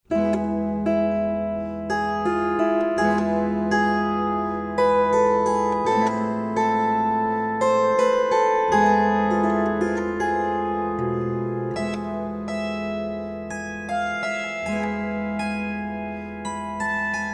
18 mélodies hébraïques à la cithare.
Musiques traditionnelles, adaptées pour cithare